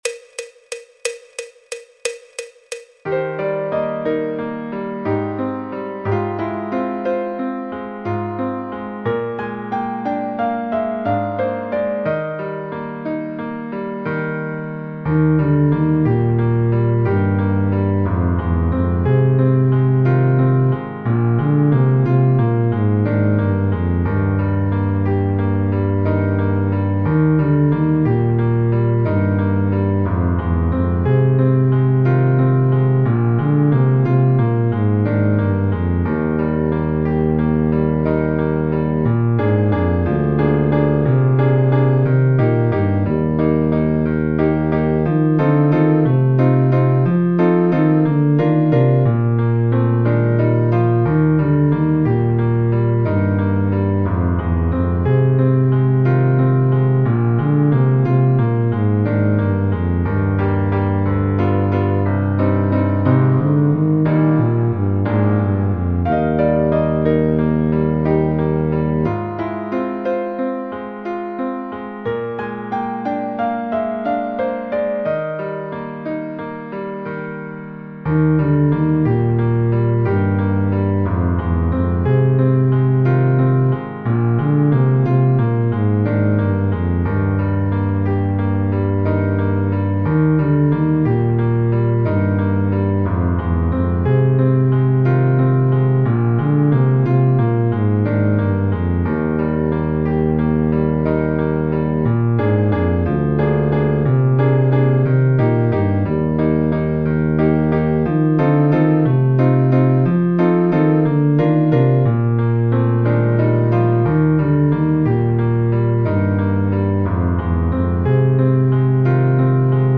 Música clásica
Contrabajo, Tuba